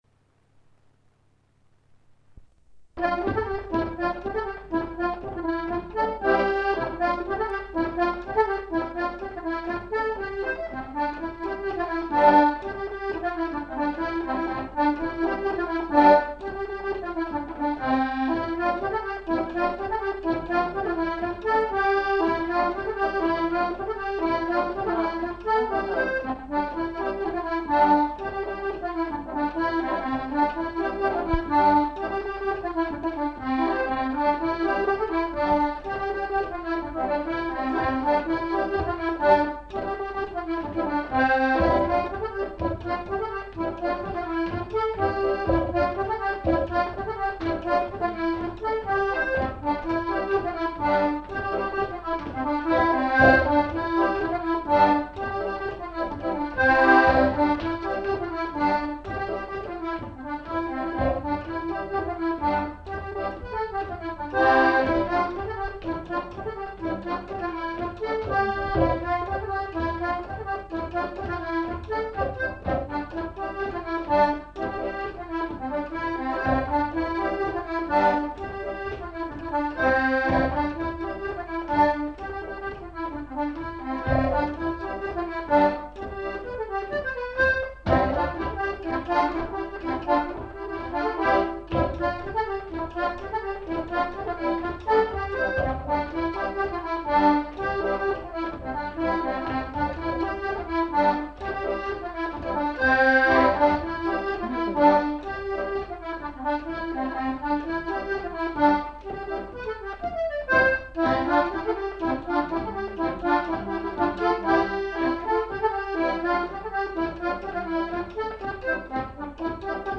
Rondeau
Lieu : [sans lieu] ; Gers
Genre : morceau instrumental
Instrument de musique : accordéon diatonique
Danse : rondeau